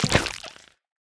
Minecraft Version Minecraft Version snapshot Latest Release | Latest Snapshot snapshot / assets / minecraft / sounds / block / honeyblock / step4.ogg Compare With Compare With Latest Release | Latest Snapshot